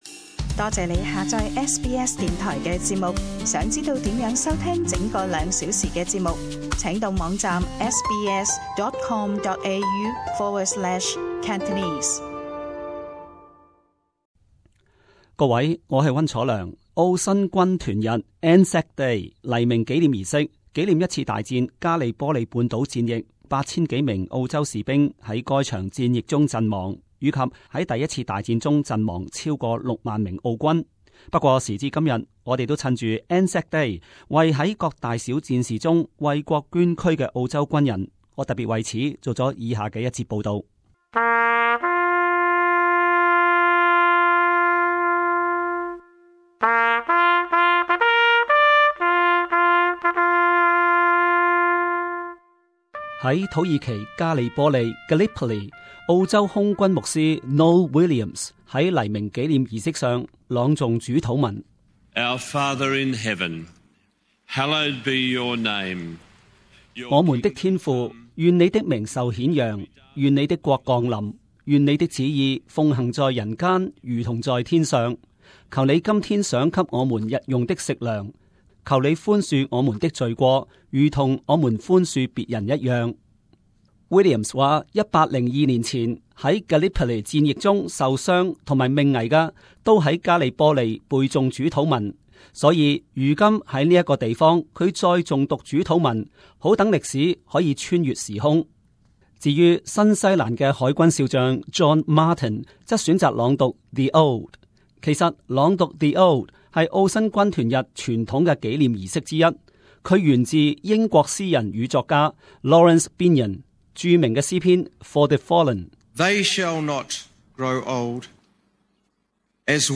【時事報導】 澳新軍團日參加人數少了卻不減熱情